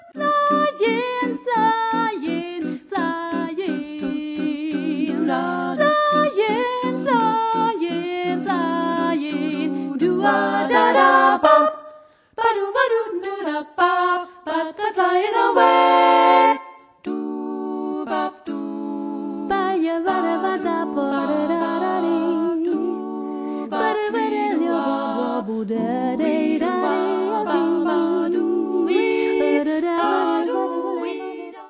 a cappella jazz